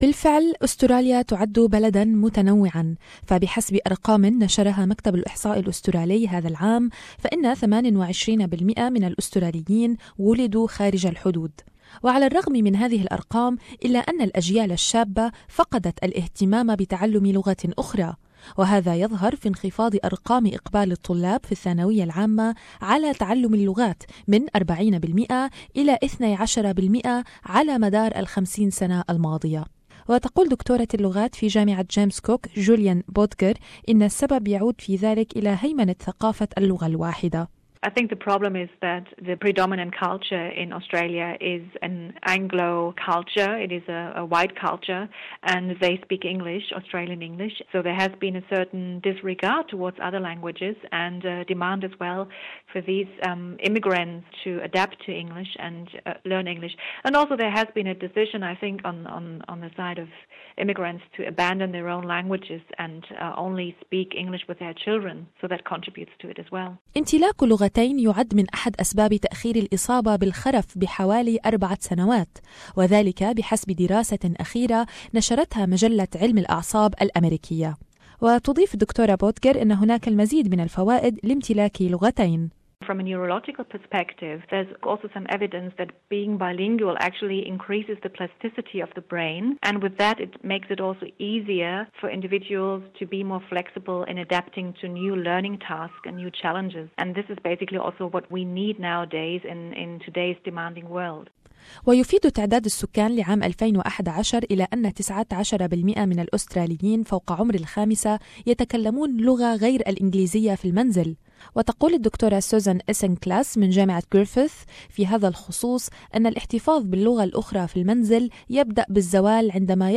يٌعدّ المجتمع الأسترالي مجتمعاً متعدداً حيث يتحدث السكان حوالي 300 لغة مما يجعل أستراليا من أكثر الدول تعددا لغويا وثقافيا في العالم. ومع ذلك إلا أن الخبراء يحذرون من احتمالية التوجه إلى ثقافة اللغة الواحدة وهي الانجليزية بحيث تغني عن أي لغة أخرى. للمزيد في تقرير